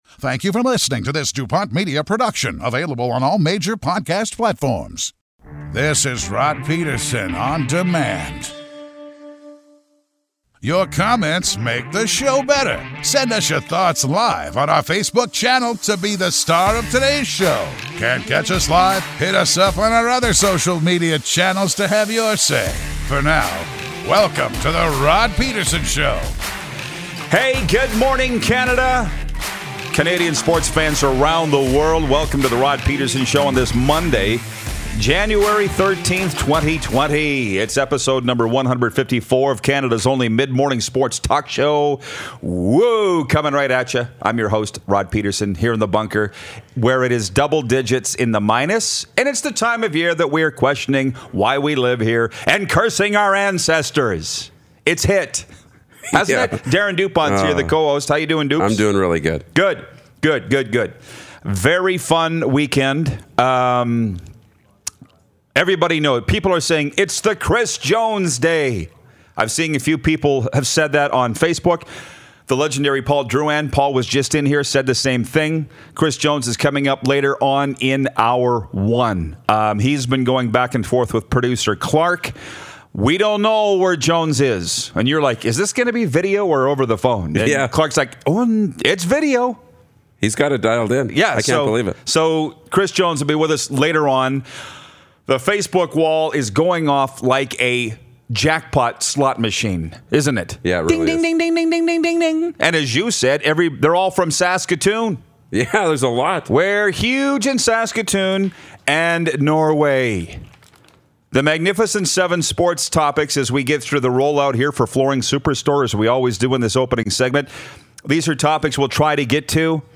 All-Time CFL Reception King, Nike Lewis is here! Chris Jones, 4x Grey Cup Champion, gives us a call!